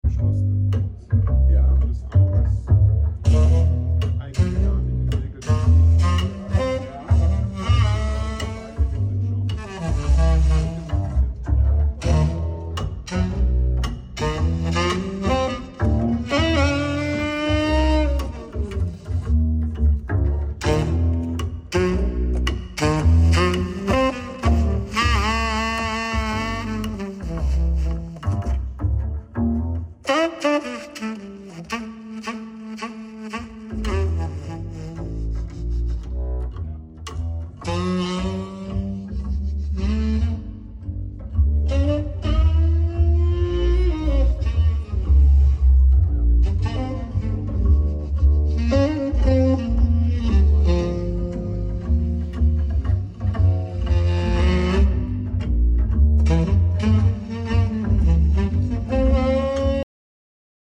Beautiful Sounding Audiophile HiEnd Home Stereo System